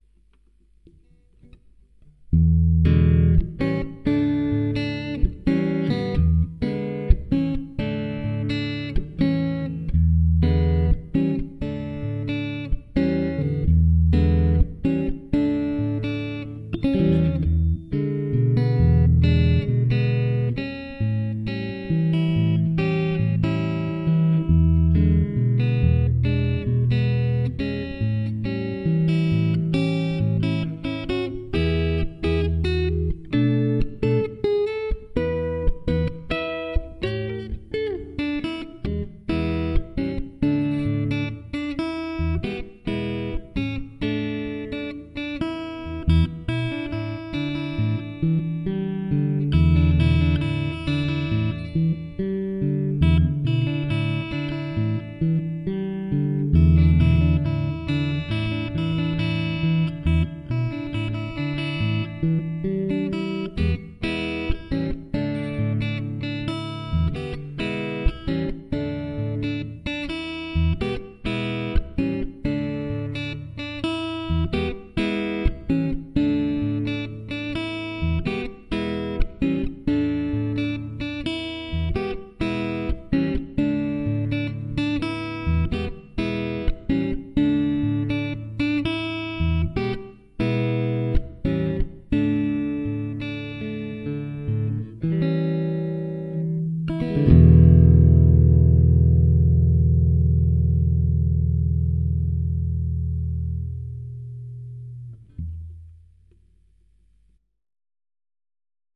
そんな曲をソロギターで弾いてみました！